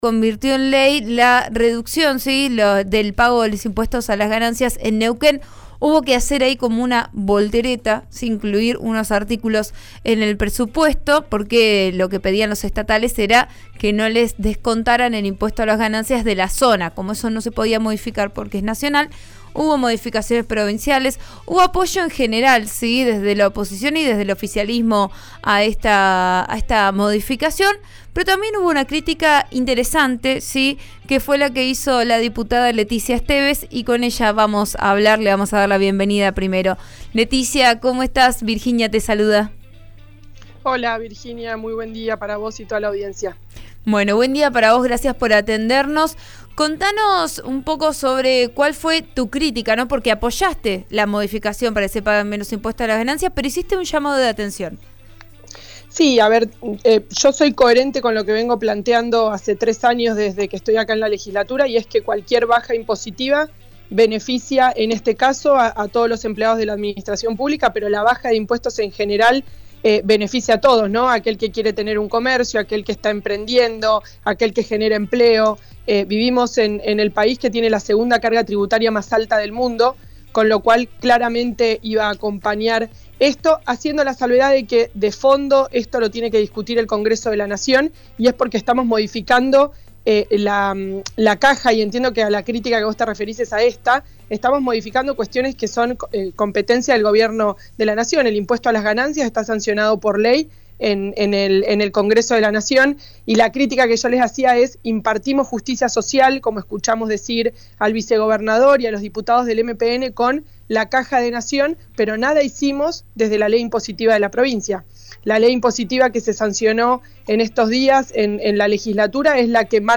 La diputada estuvo al aire de 'Vos a Diario'. Explicó que el PRO y Nuevo Compromiso acompañan a Figueroa como gobernador. Pidió no dramatizar una posible división de Juntos por el Cambio.